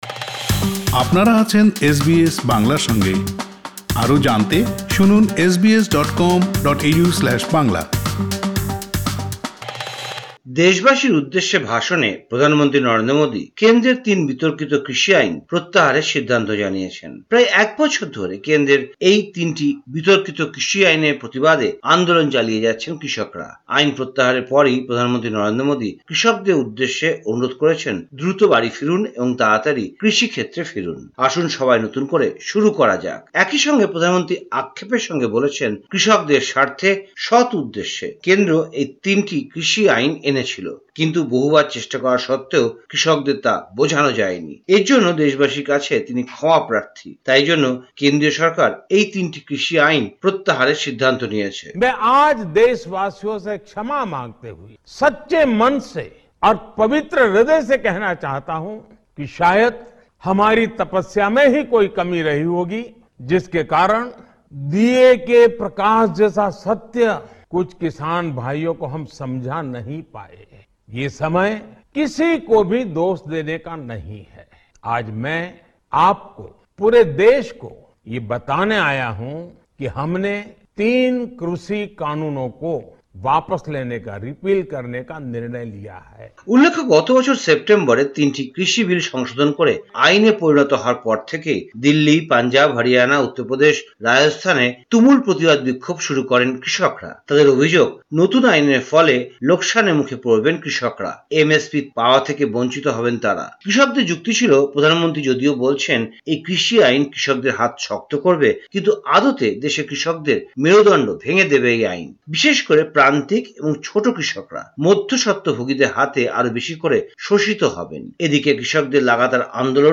কলকাতা থেকে